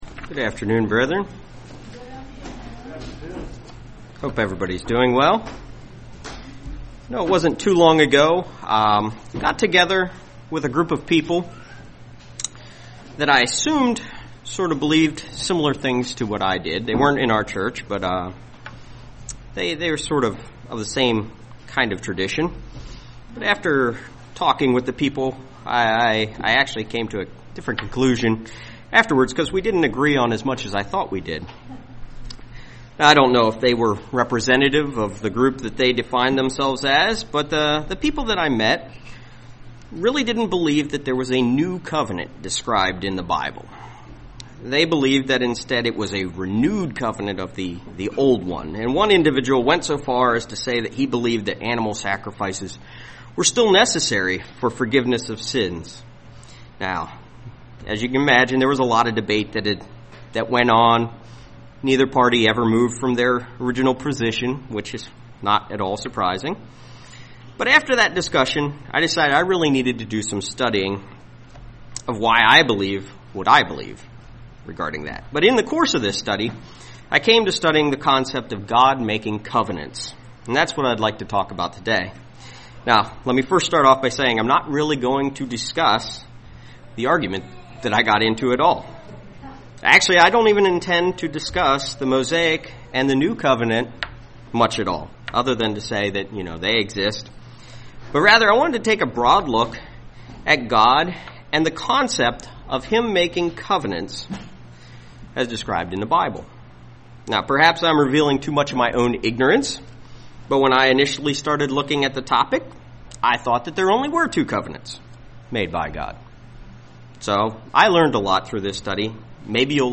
UCG Sermon Studying the bible?
Given in Lehigh Valley, PA